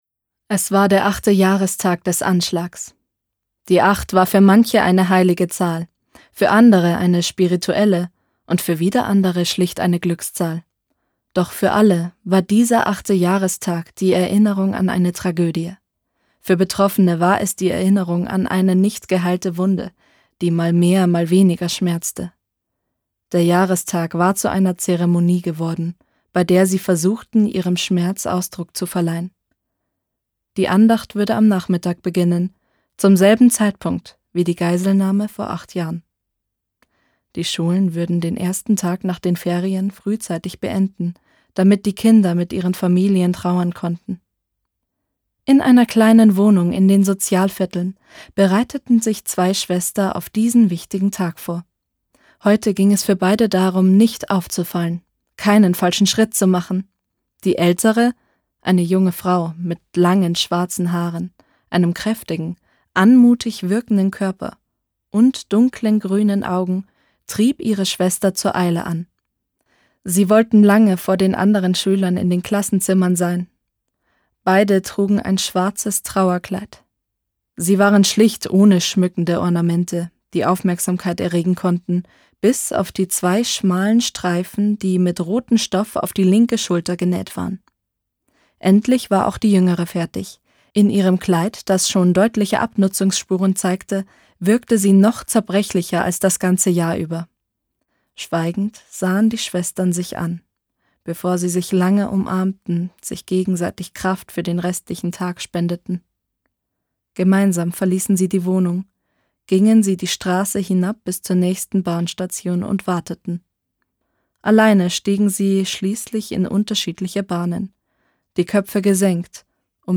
Voice Over Demo